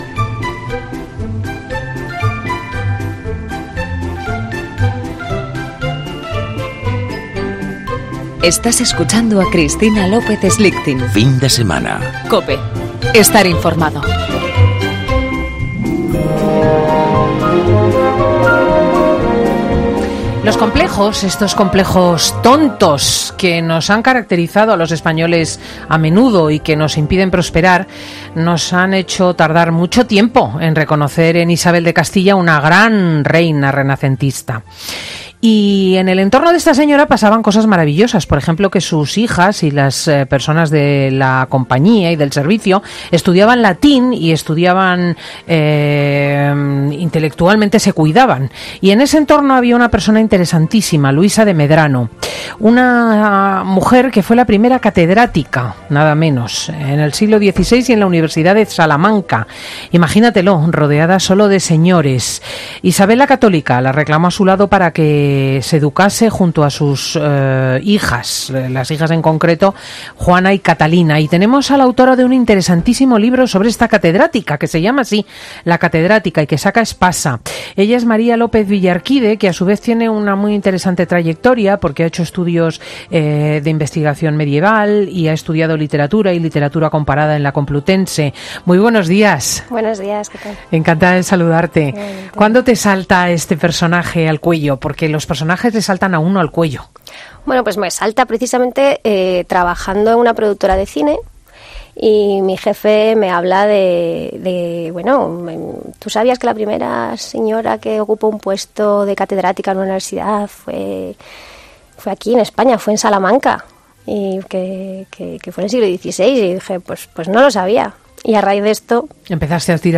Merece la pena que redescubramos juntos cómo la fue la vida de Luisa de Medrano, a quien Isabel la Católica reclamó a su lado para que se educase junto a sus dos hijas. Escucha ahora 'El comentario de Cristina L. Schlichting', emitido el 6 de mayo, en FIN DE SEMANA .